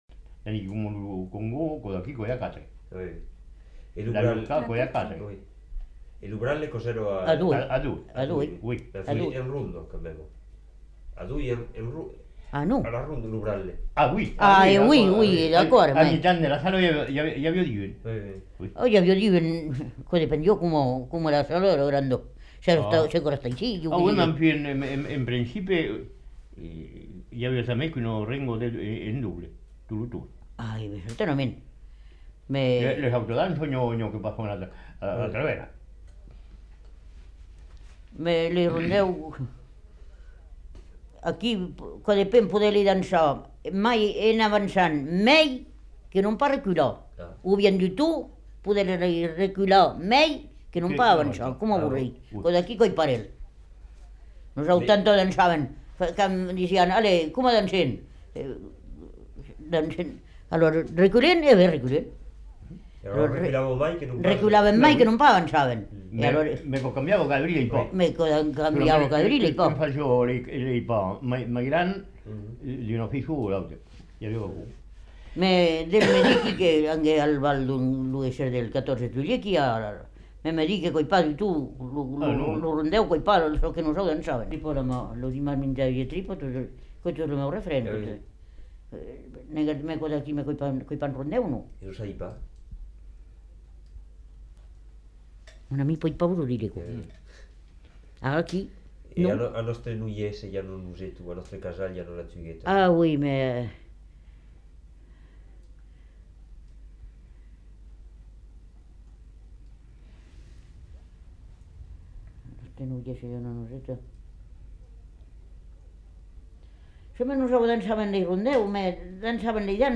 Aire culturelle : Haut-Agenais
Lieu : Roumagne
Genre : témoignage thématique